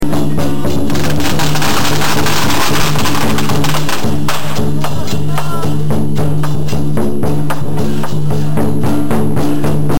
福を運ぶ中国獅子舞の舞は、爆竹の賑やかな音と共に披露される。
♪ 中国獅子舞